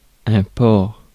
Ääntäminen
France: IPA: [pɔʁ]